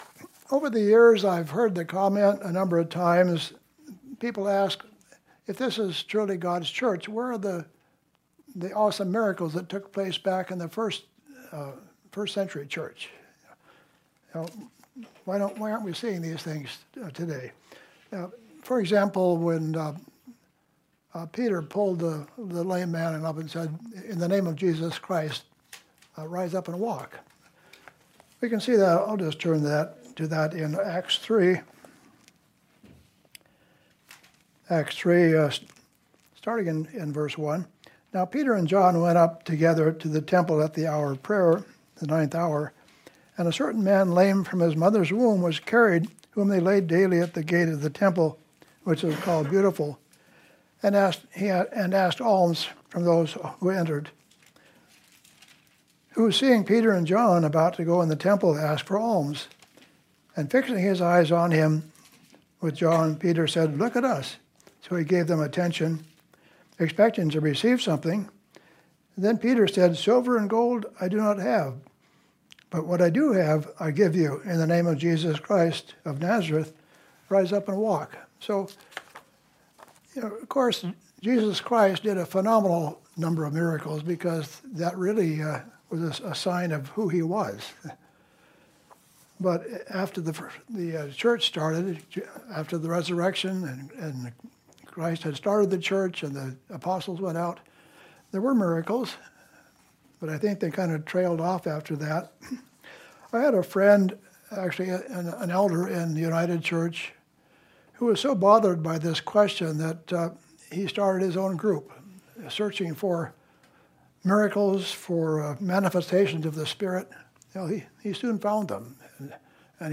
New Sermon | PacificCoG
From Location: "Kennewick, WA"